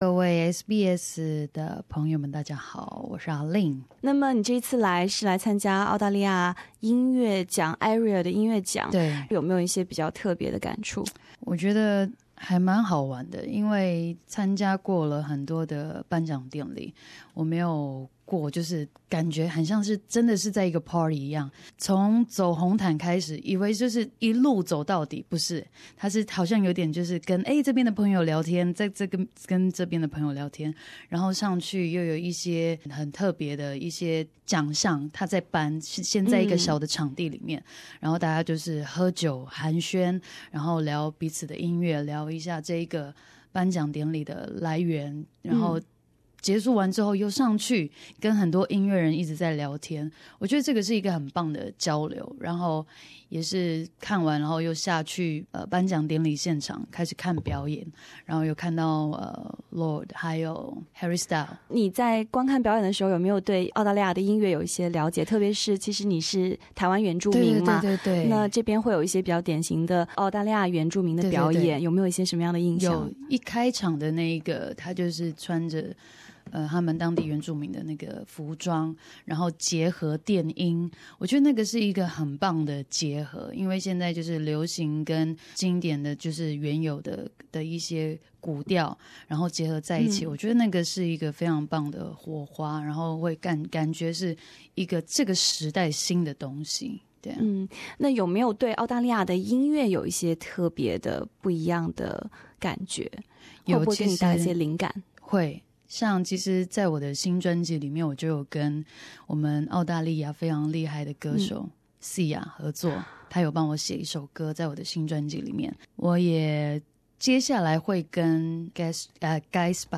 台湾流行歌手ALIN作客SBS普通话演播间
SBS 普通话电台